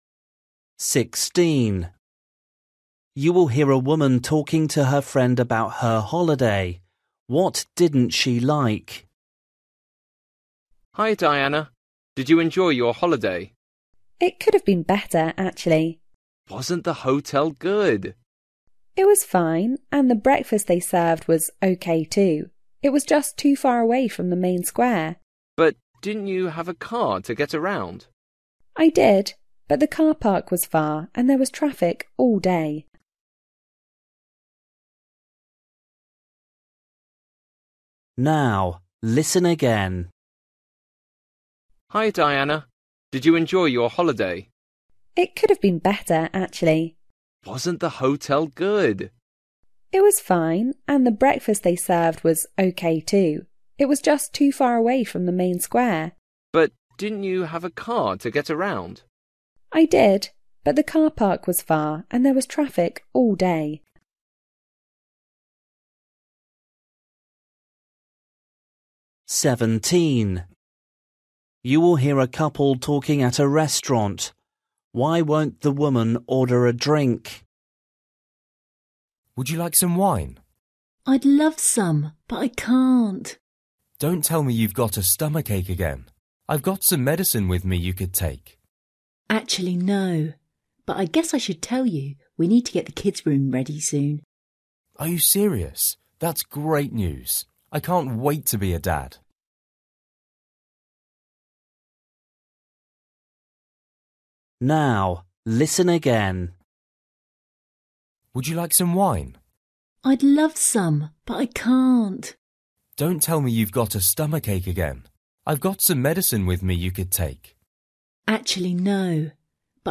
Listening: everyday short conversations
16   You will hear a woman talking to her friend about her holiday. What didn’t she like?
17   You will hear a couple talking at a restaurant. Why won’t the woman order a drink?
18   You will hear two friends talking outside a shop. Why is the man unhappy?
19   You will hear a woman talking on the phone. What is wrong with the dress?
20   You will hear a man talking to his wife about her handbag. What is the man’s problem?